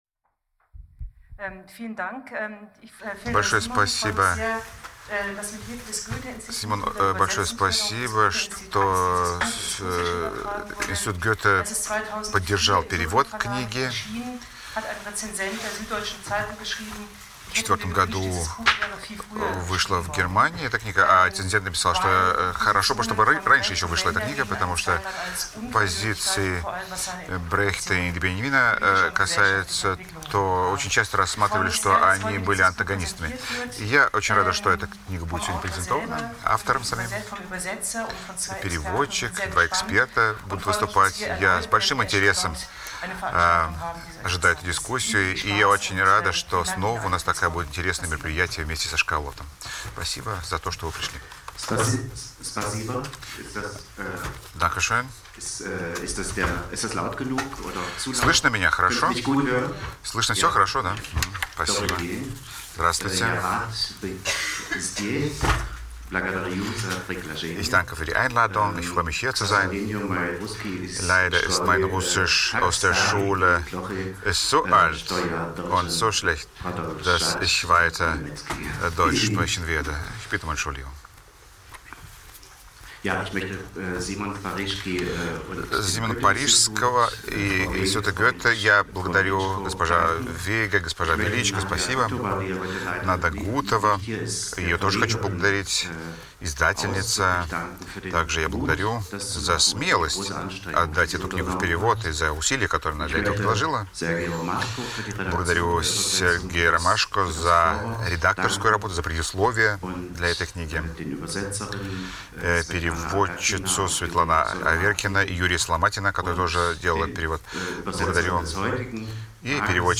Аудиокнига Бертольт Брехт и Вальтер Беньямин | Библиотека аудиокниг